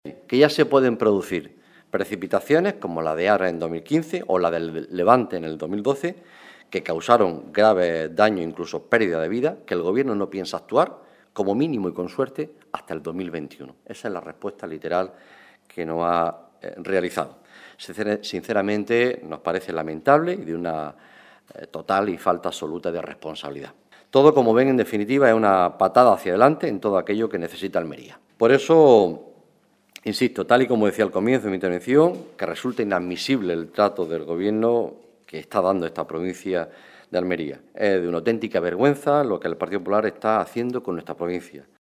Rueda de prensa sobre infraestructuras hídricas que ha ofrecido el senador del PSOE de Almería, Juan Carlos Pérez Navas, junto a la diputada nacional, Sonia Ferrer